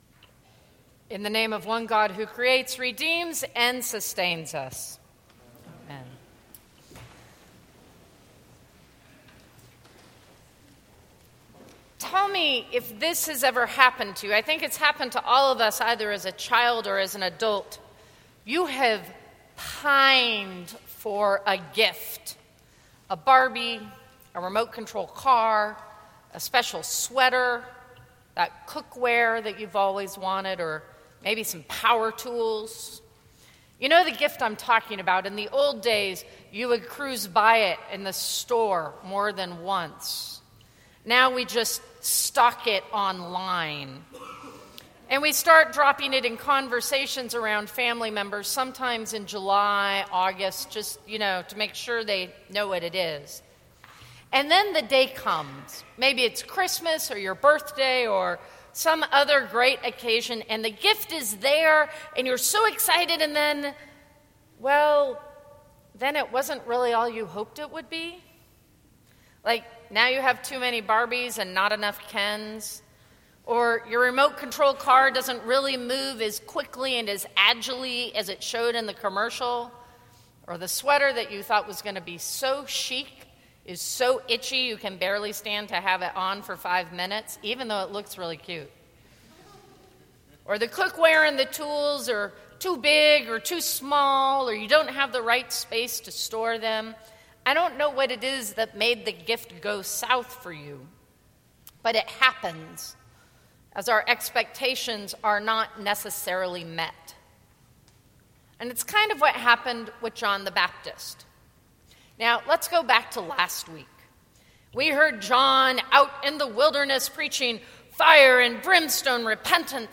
Sermons from St. Cross Episcopal Church 12/15/2013 Dec 18 2013 | 00:10:28 1x 00:00 / 00:10:28 Subscribe Share Apple Podcasts Spotify Overcast RSS Feed Share Link Embed